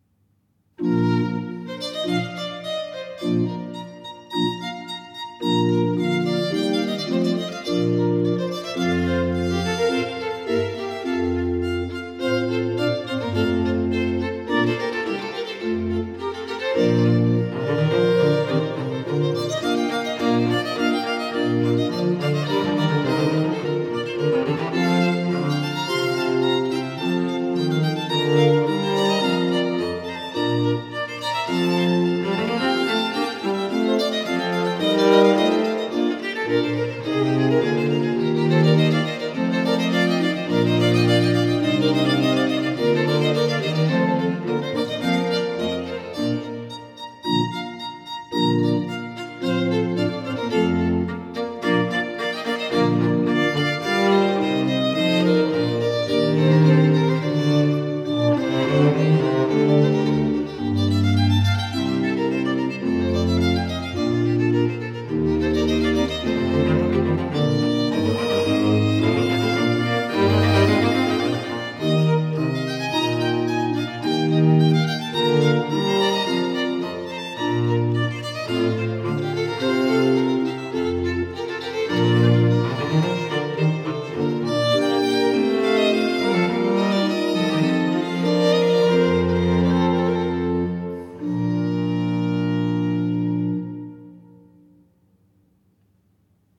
Allegro